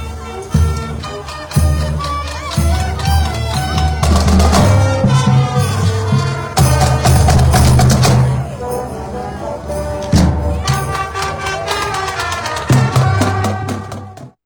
Downtown Emporia was a festive place for the Emporia Area Chamber of Commerce’s 47th annual Christmas Parade on Tuesday.
8581-band-sound.wav